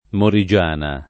morigiana [ mori J# na ]